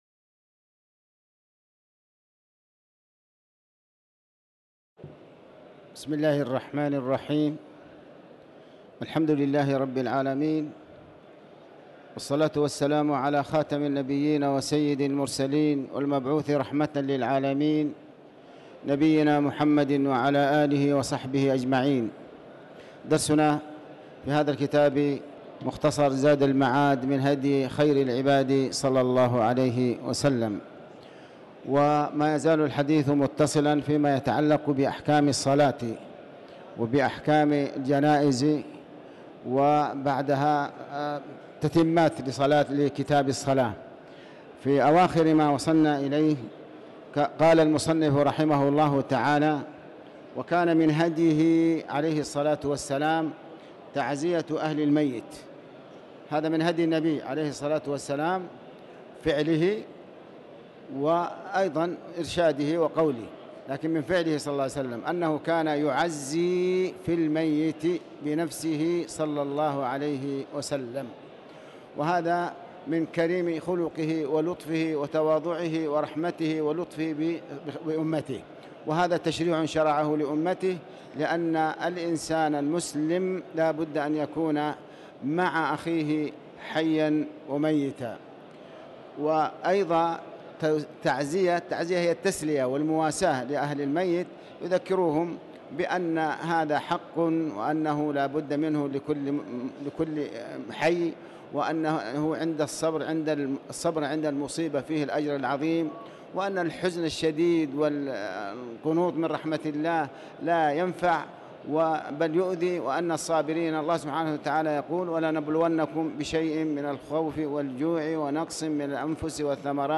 تاريخ النشر ٢٩ جمادى الآخرة ١٤٤٠ هـ المكان: المسجد الحرام الشيخ